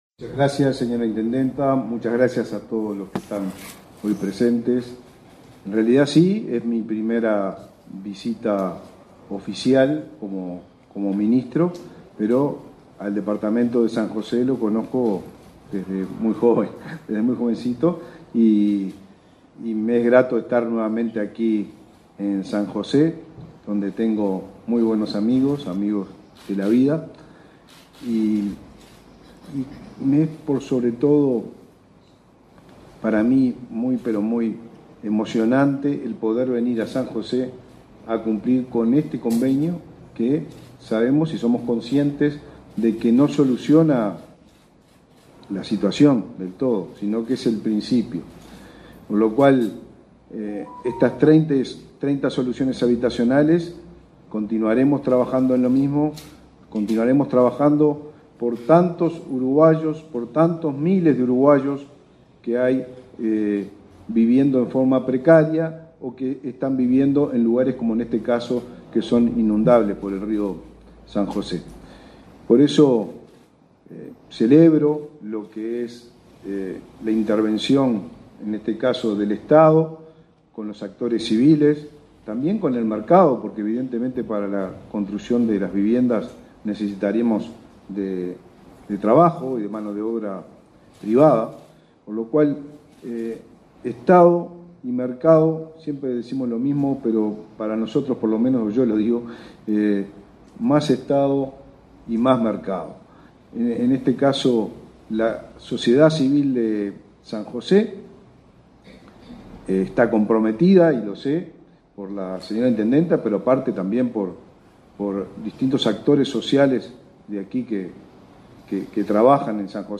Palabras del ministro de Vivienda y Ordenamiento Territorial, Raúl Lozano